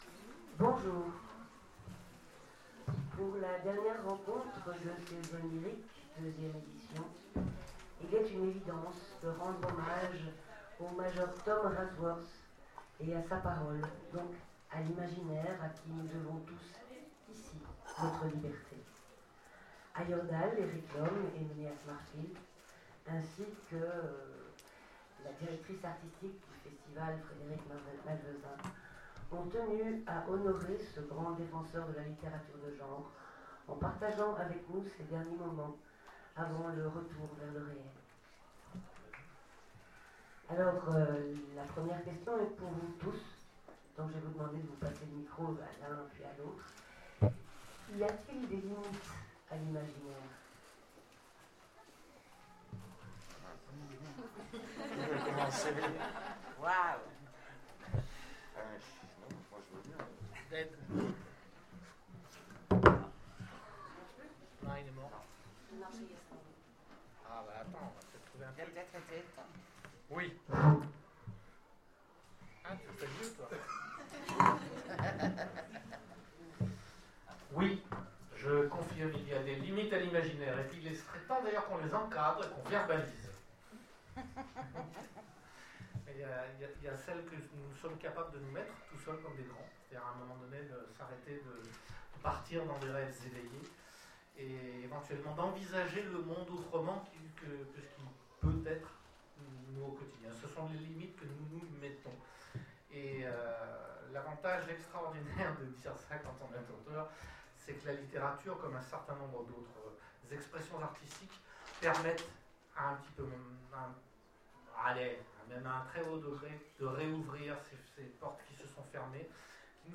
Les Oniriques 2015 : Table ronde L’imaginaire...